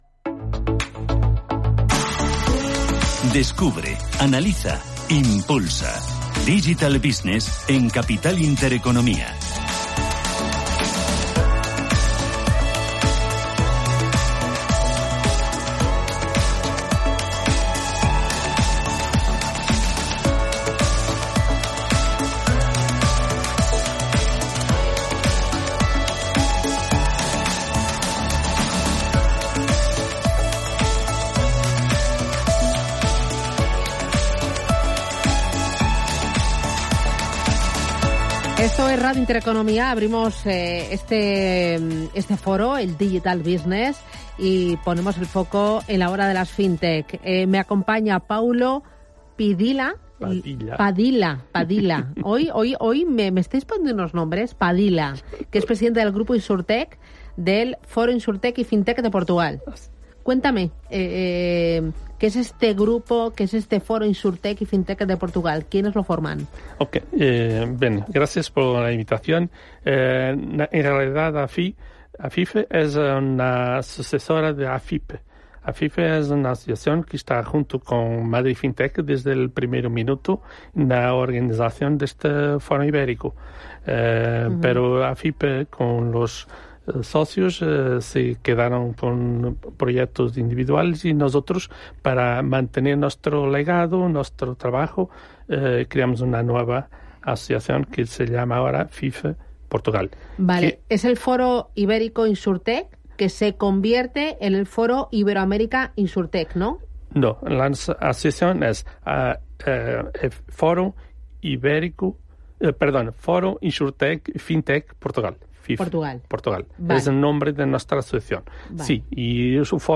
Se conectó en directo con el Auditorio de Santalucía, en Madrid, para recoger las claves del Foro Iberoamérica InsurTech, con invitados en plató y entrevistados en el propio evento.